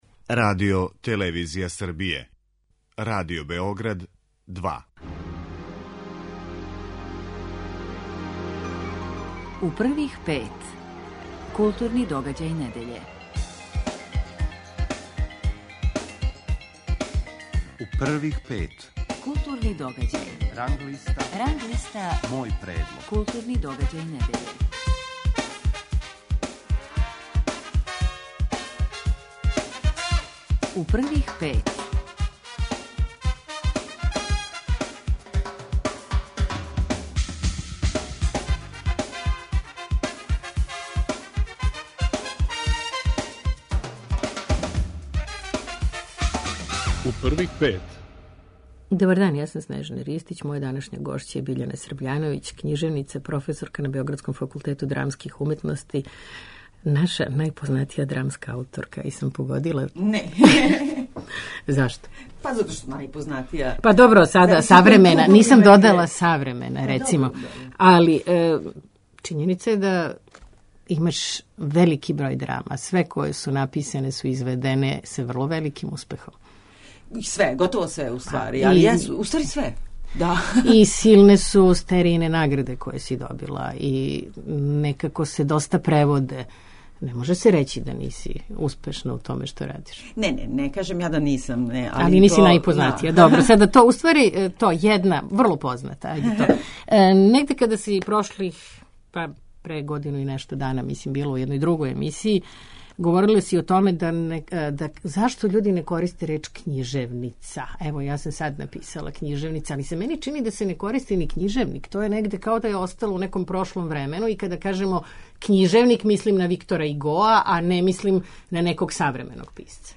Гошћа је књижевница, професорка на београдском Факултету драмских уметности и наша напознатија драмска ауторка Биљана Србљановић.
Новинари и критичари Радио Београда 2 издвајају најбоље, најважније културне догађаје у свим уметностима у протеклих седам дана и коментаришу свој избор.